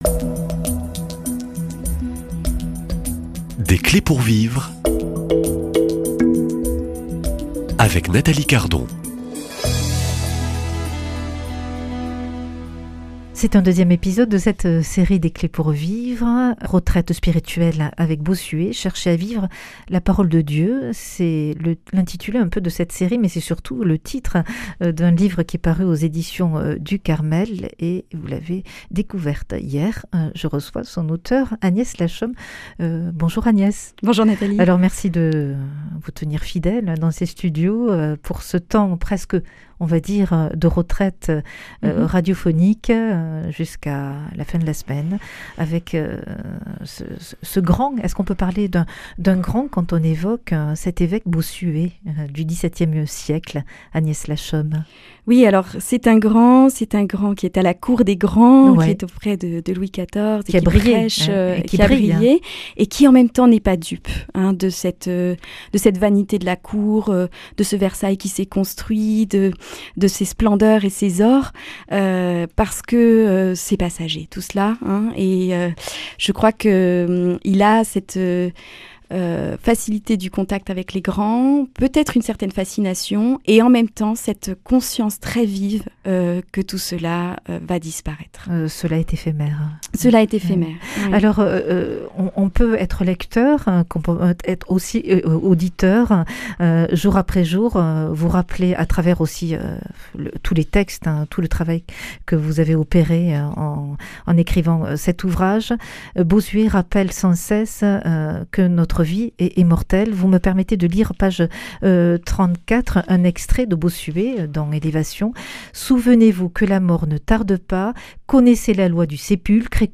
Invitée